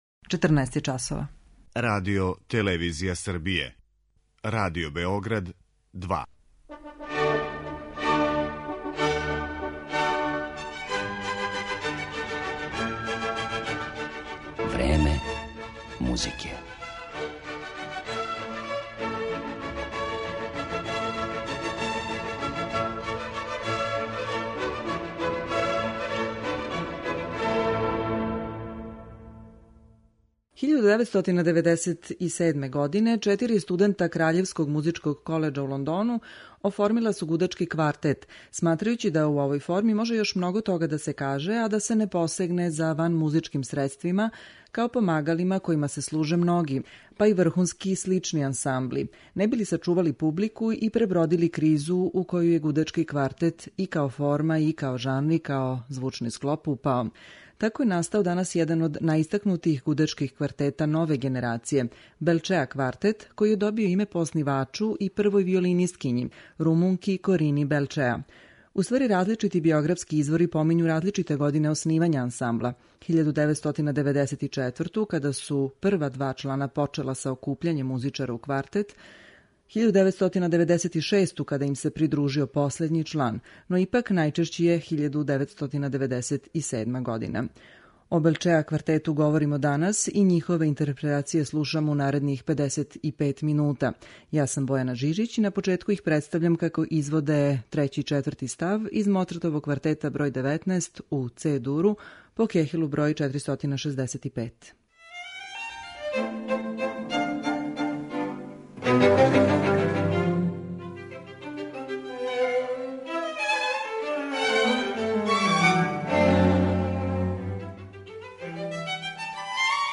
Емисија је посвећена једном од најбољих камерних ансамбала данашњице и добитнику престижне 'Ехо класик' награде за 2016. годину, гудачком квартету 'Белчеа', који носи име свог оснивача, виолинисткиње Корине Белчеа.